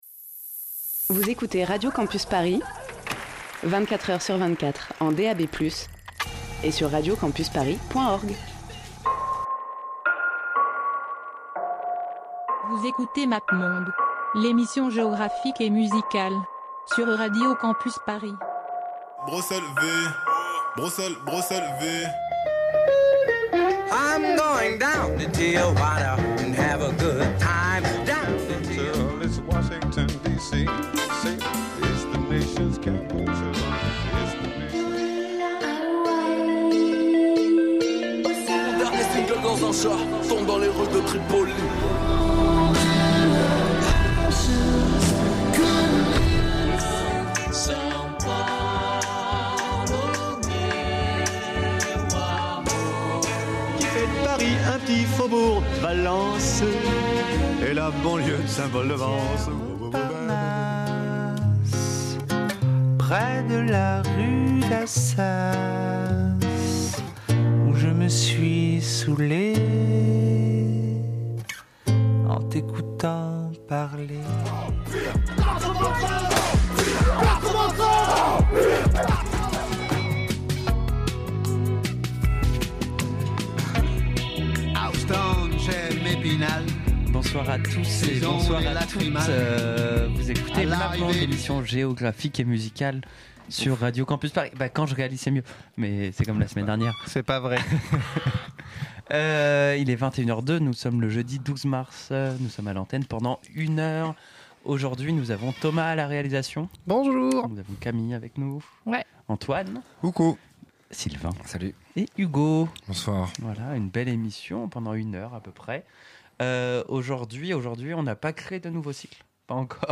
Au menu, pas de trip hop (on en a déjà parlé) mais de la jungle, du breakbeat, de la drum and bass, de la dubstep, de l'ambient, du rock, du punk et des trucs bien kitschouilles.